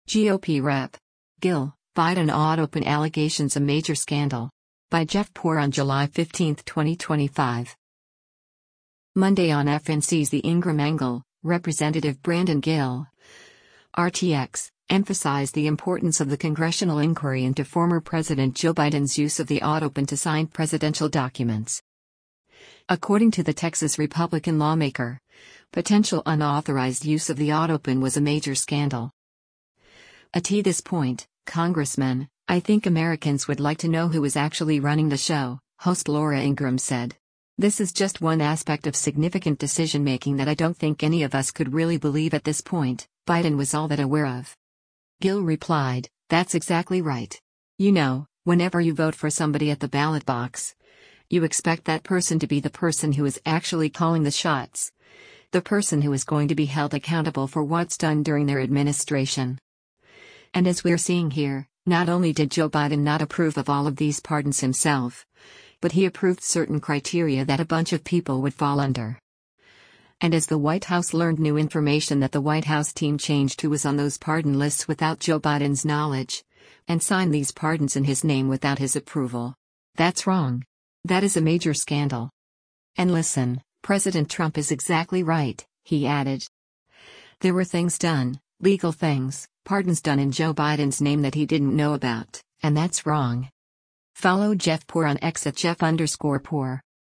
Monday on FNC’s “The Ingraham Angle,” Rep. Brandon Gill (R-TX) emphasized the importance of the congressional inquiry into former President Joe Biden’s use of the autopen to sign presidential documents.